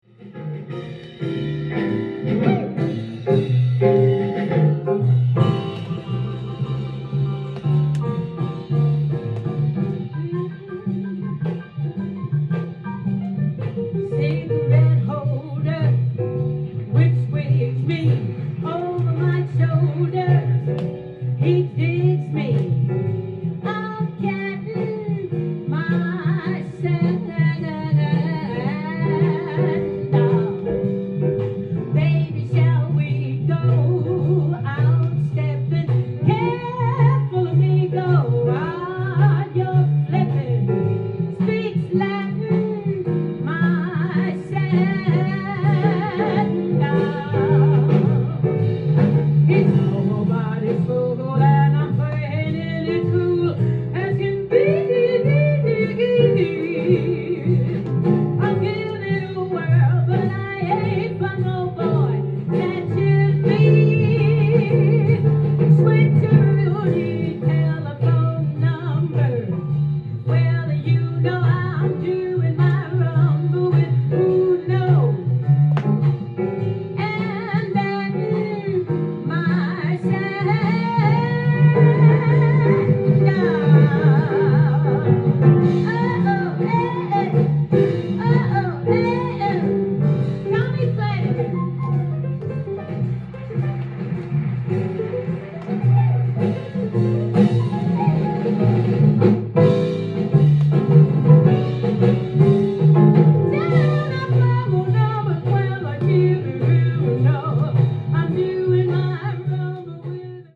ジャンル：JAZZ-VOCAL
店頭で録音した音源の為、多少の外部音や音質の悪さはございますが、サンプルとしてご視聴ください。